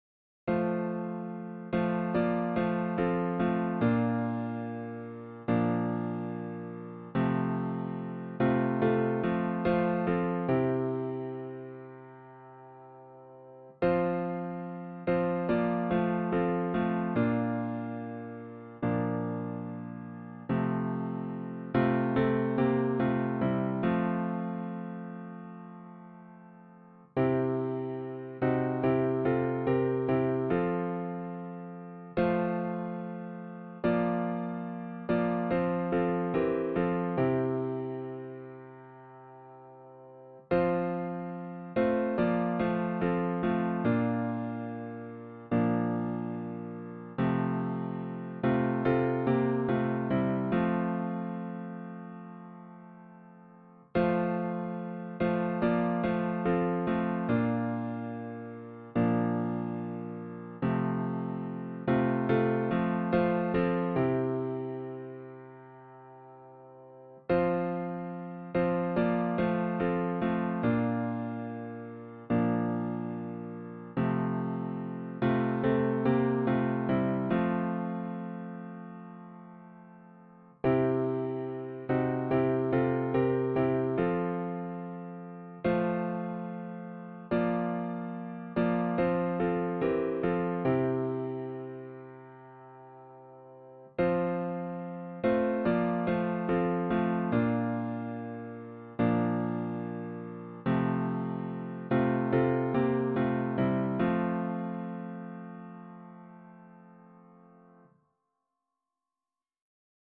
hymn
for piano